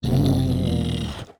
add SFX